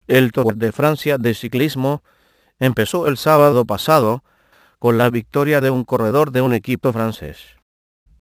Texte de d�monstration lu par Alberto (AT&T Natural Voices; distribu� sur le site de Nextup Technology; homme; espagnol latino am�ricain)